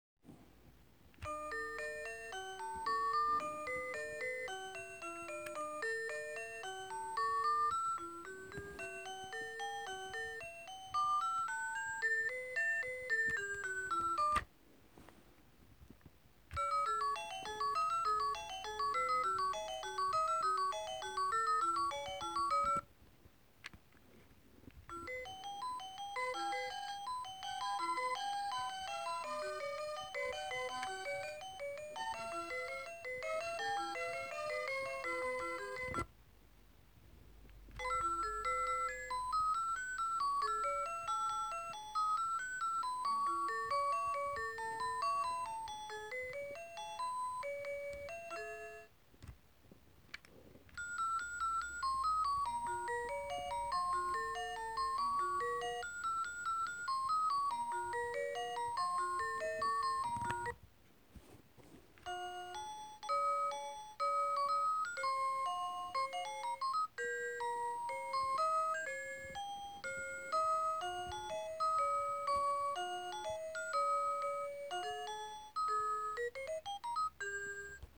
Nový model SRP312 vás vzbudí melodií.
Duální buzení: světlo + zvuk Při buzení mění plynule barvu světla Buzení jednou ze 6 melodií, které se střídají.
melodie-budik-JVD.mp3